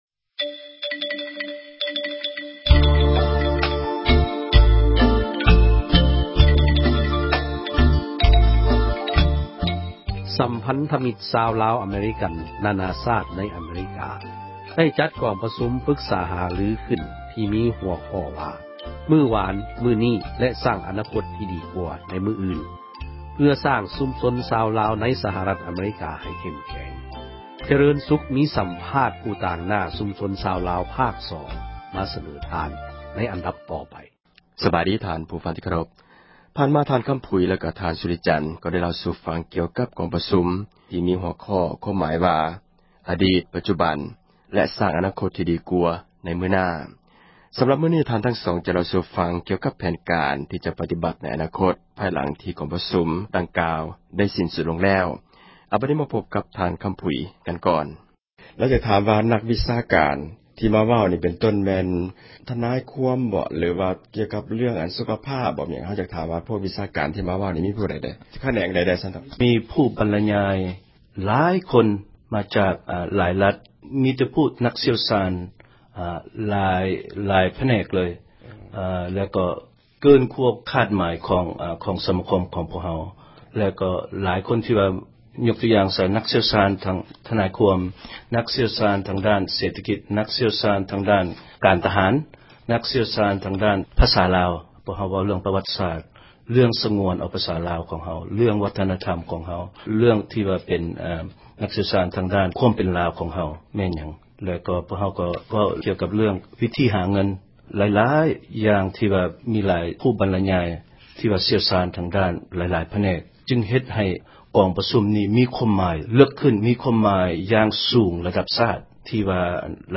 ສັມພາດຜູ້ຕາງໜ້າ ຊຸມຊົນຊາວລາວ ຕອນສອງ